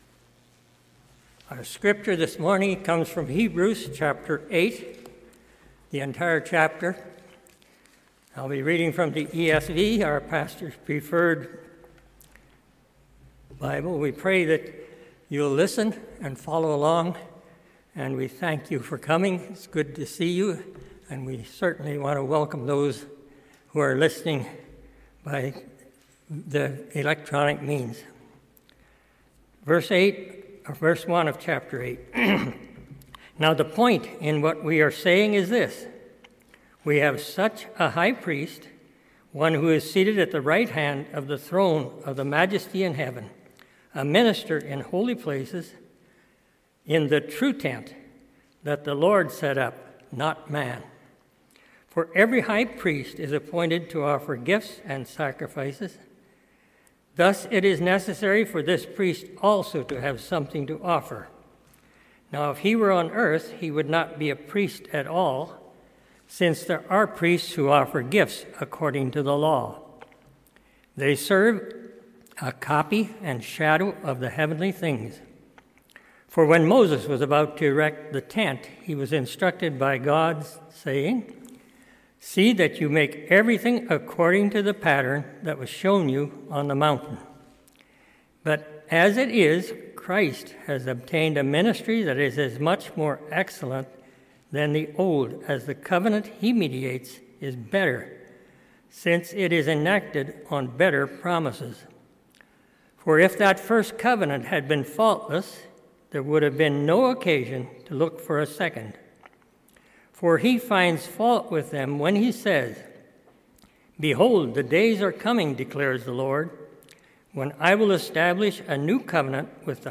MP3 File Size: 31.1 MB Listen to Sermon: Download/Play Sermon MP3